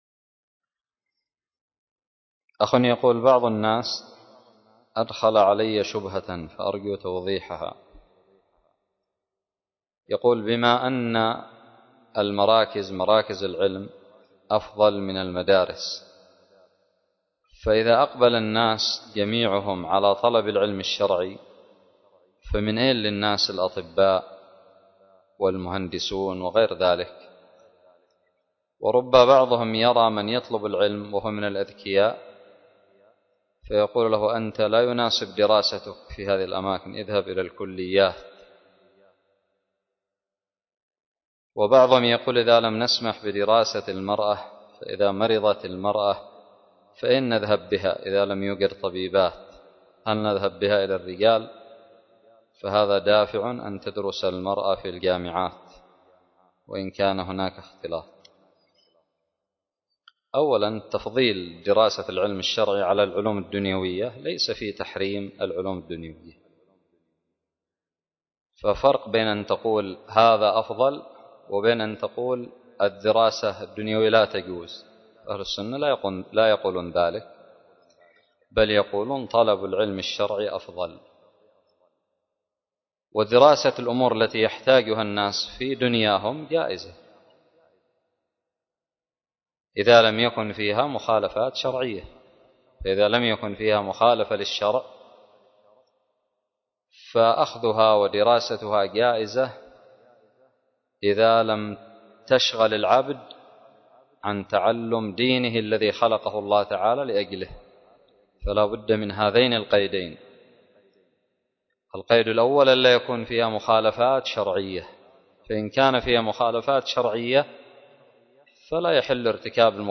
فتاوى عامة